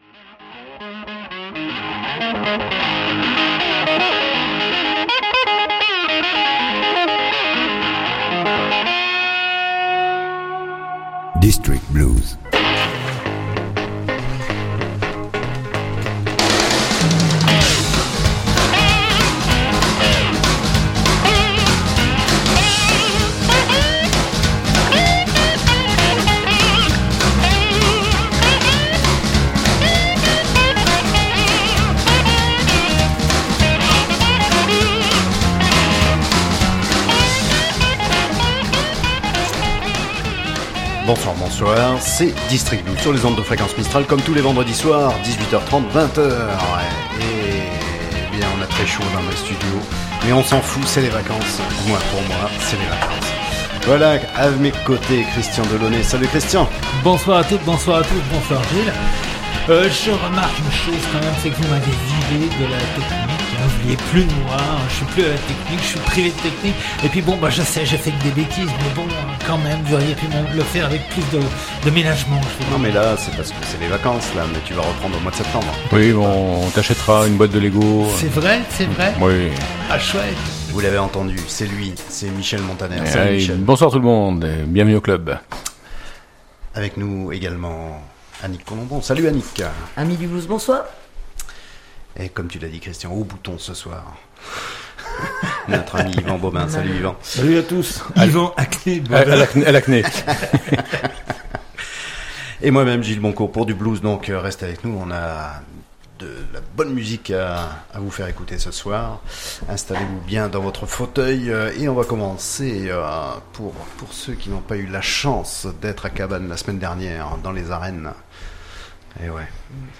Le blues sous toutes ses formes, le blues sous toutes ses faces, voilà le credo d’Eden District Blues, qu’il vienne de Chicago, de Milan, du Texas ou de Toulouse, qu’il soit roots, swamp, rock ou du delta…
« DISTRICT BLUES », une émission hebdomadaire, tous les vendredis de 18h30 à 20h .